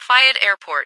- New ATIS Sound files created with Google TTS en-US-Studio-O